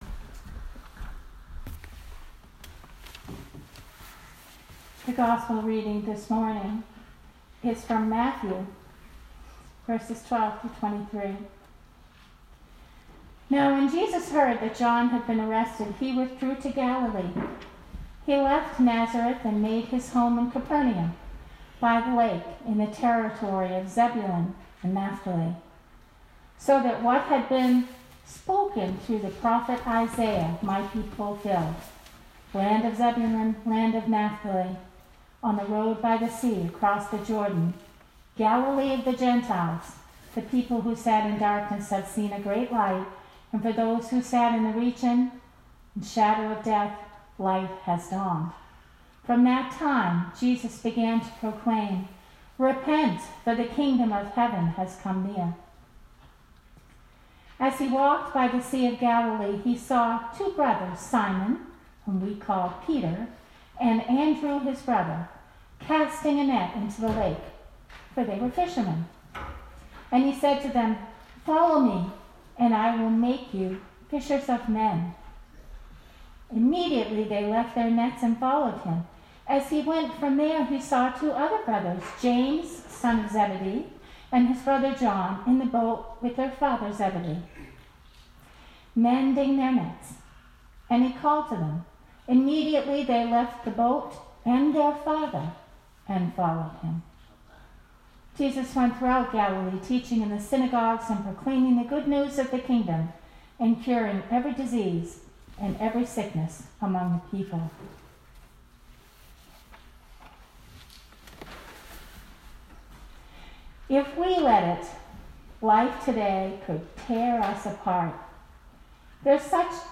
Sermon 2020-01-26
Sermon